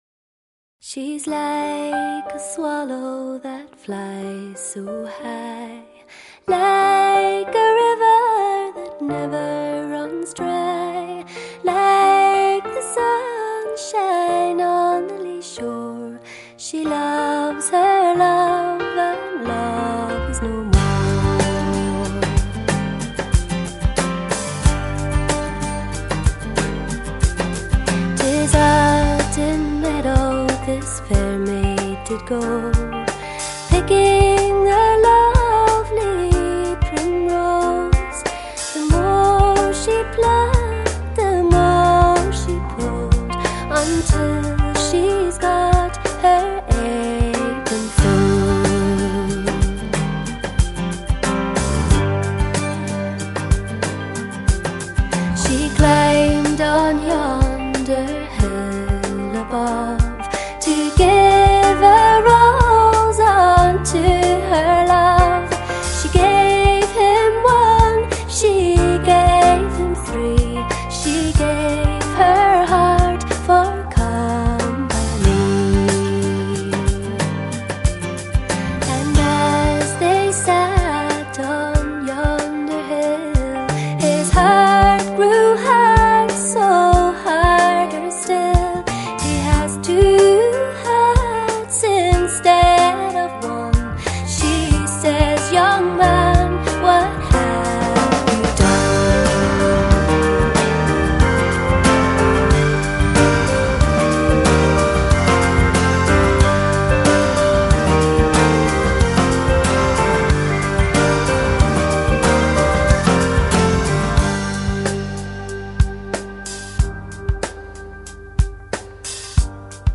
很好听啊， 声音不错，唱功也好，谢谢分享哦